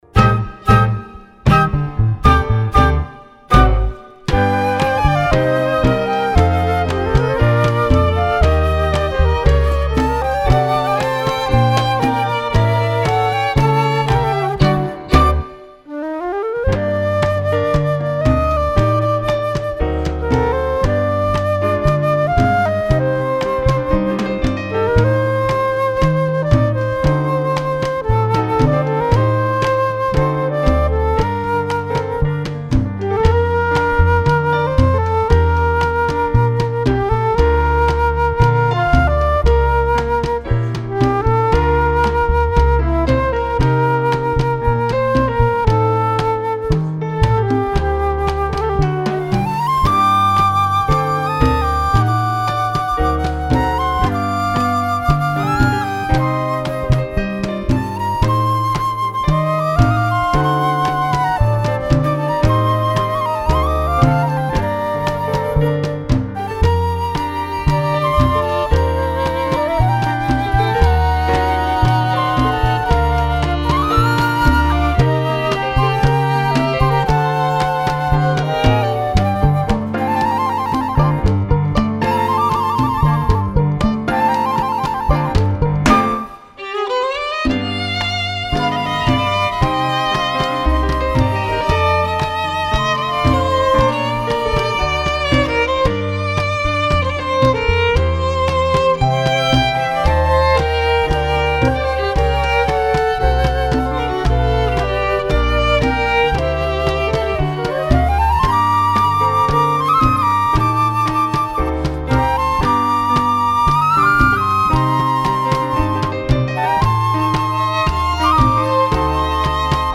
Category: combo (septet)
Style: danzón
Solos: open